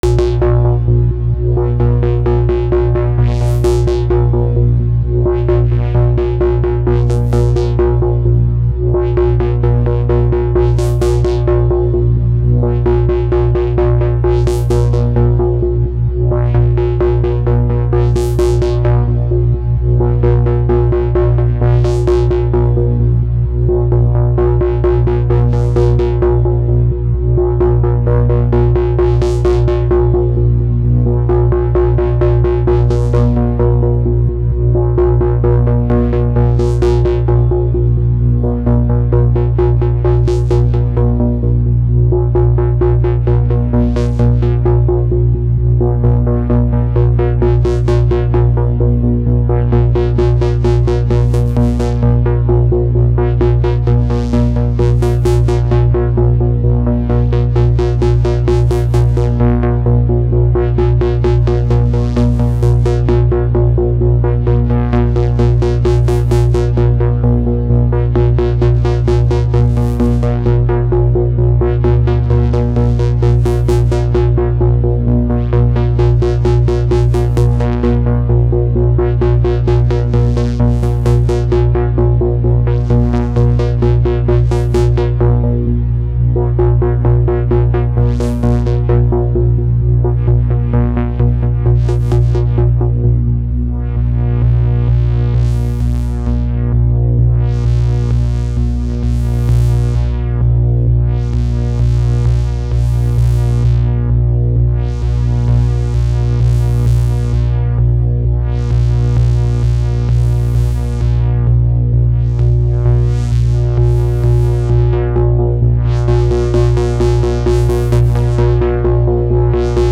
2 x malevolent VCO
Shakmat Ringmod
Doepfer SSI filter
Quadraverb.